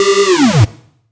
VEC3 Alarm FX
VEC3 FX Alarm 05.wav